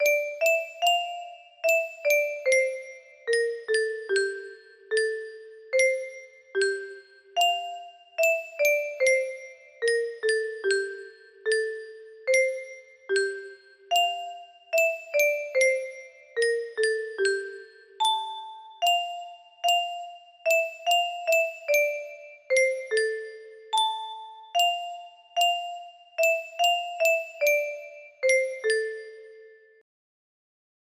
A melody music box melody